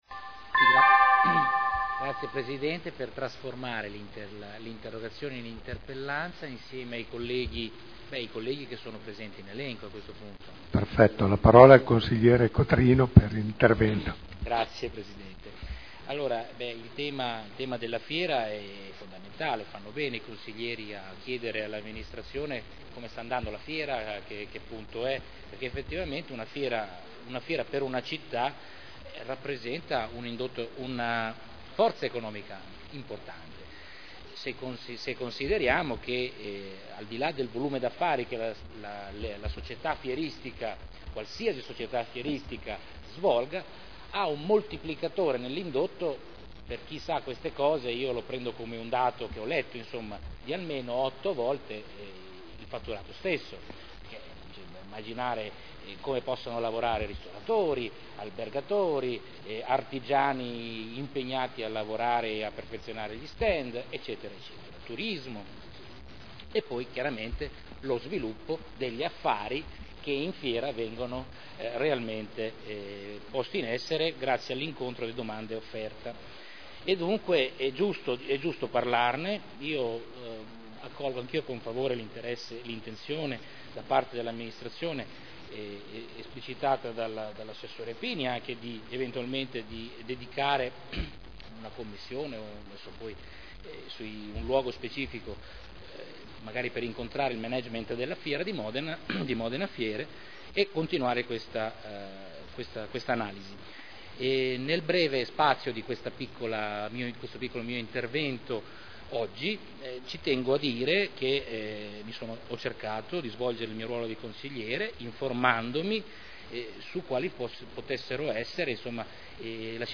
Seduta del 24/01/2011.